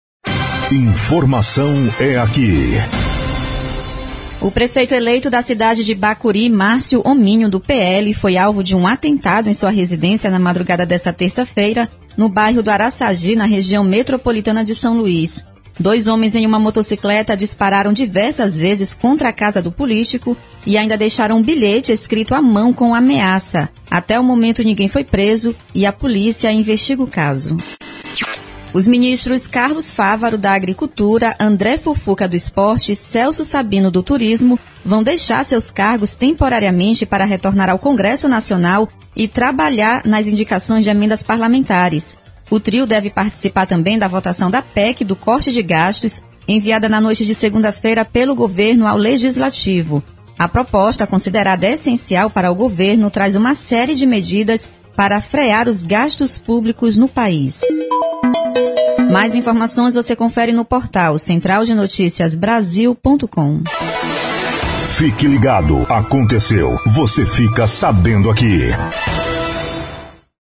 Todos os Plantões de Notícias
Repórter